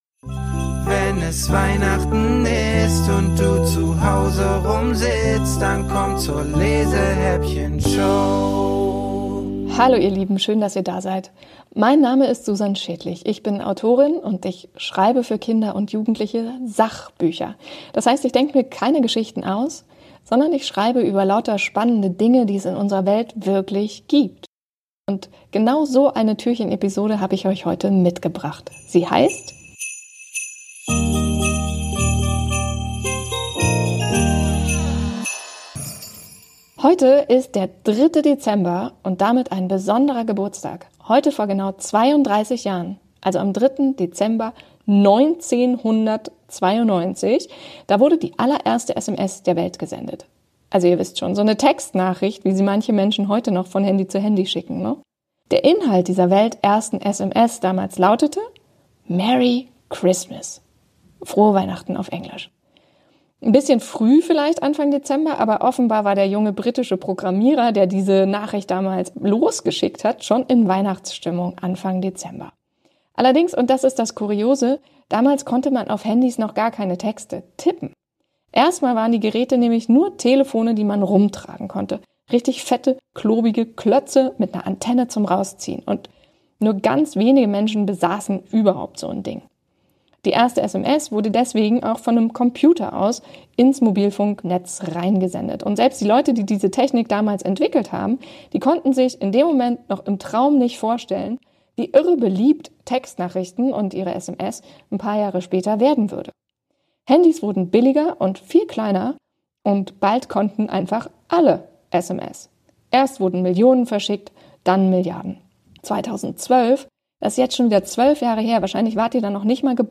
Autorinnen und Autoren höchstpersönlich vertont wurden!